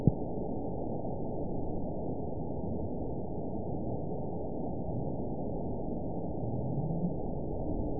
event 922858 date 04/22/25 time 23:16:15 GMT (1 month, 3 weeks ago) score 9.51 location TSS-AB10 detected by nrw target species NRW annotations +NRW Spectrogram: Frequency (kHz) vs. Time (s) audio not available .wav